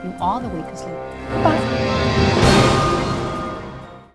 Shut Down Wavs
Wav World is the home of comedy desktop sounds.